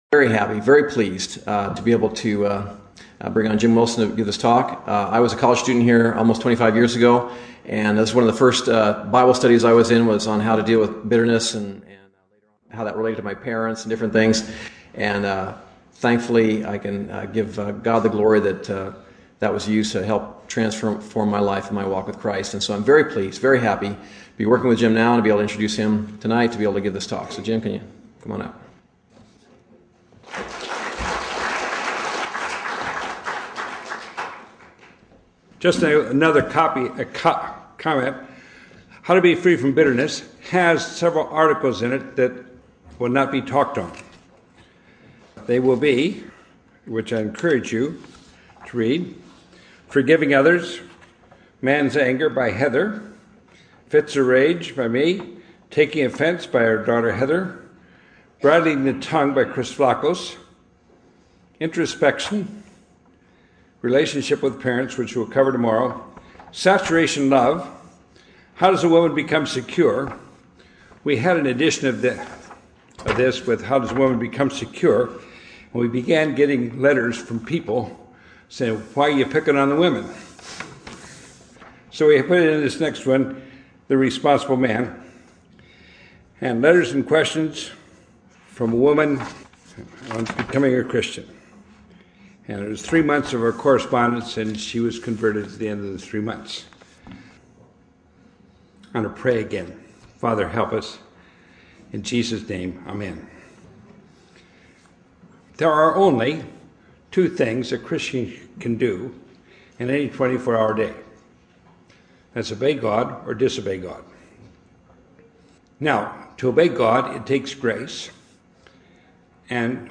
In this sermon, the speaker addresses the issue of bitterness and its negative impact on our lives.